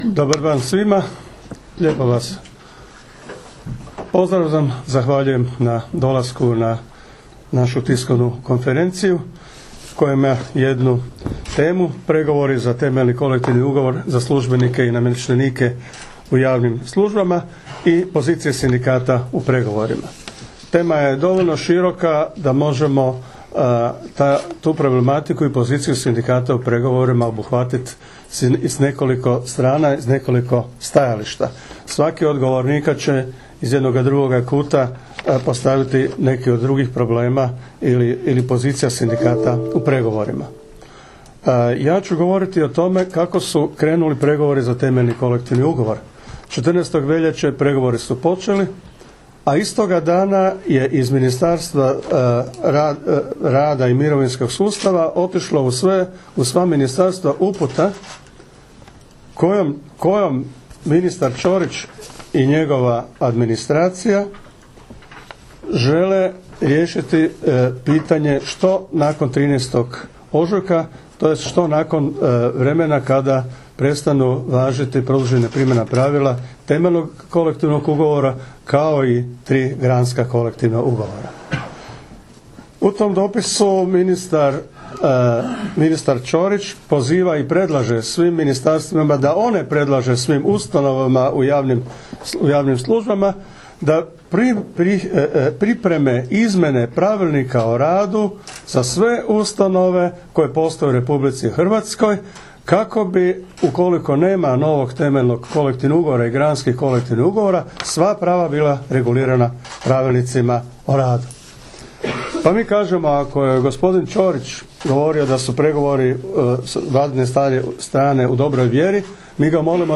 Uoči pregovora za Temeljni kolektivni ugovor s predstavnicima Vlade RH, čelnici sindikata javnih službi na konferenciji za medije održanoj 20. veljače 2017. osvrnuli su se na poziciju sindikata u pregovorima.